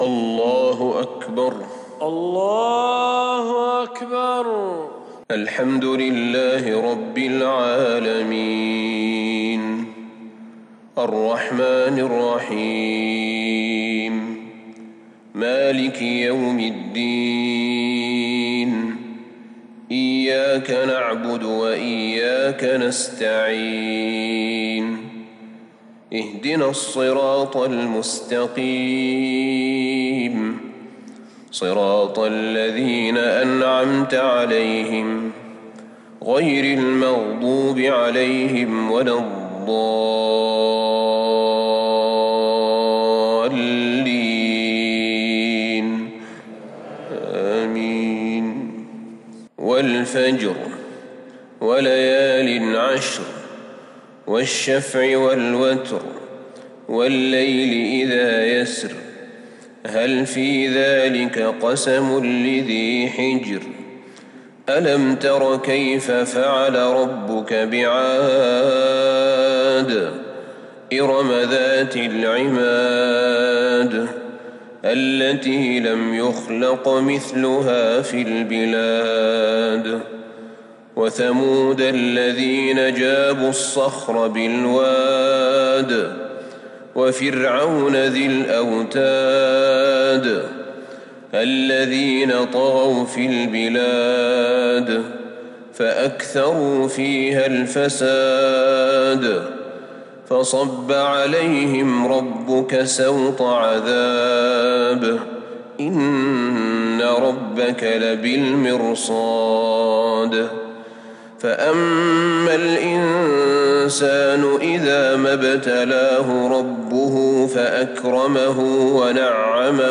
صلاة الفجر للشيخ أحمد بن طالب حميد 9 ربيع الأول 1442 هـ
تِلَاوَات الْحَرَمَيْن .